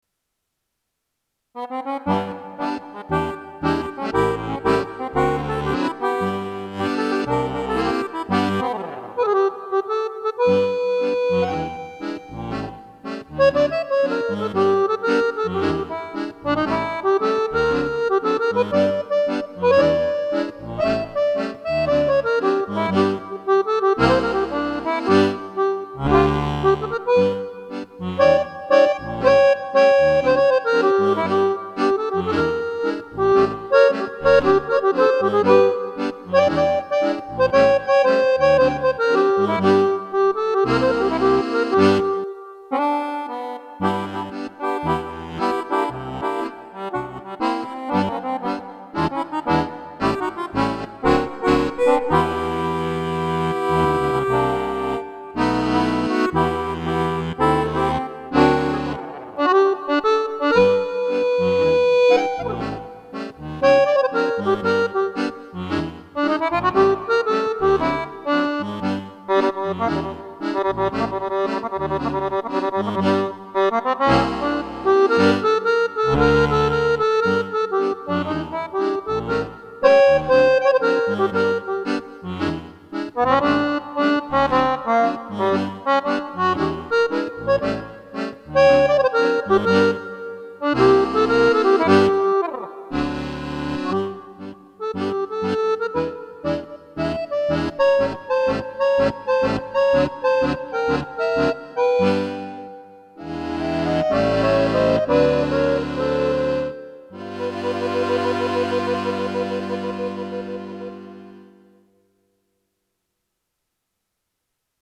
Erst wollte ich mich mehr am Original halten, dann jedoch kam der Gedanke mal wieder was Umpa-mäßiges zu machen.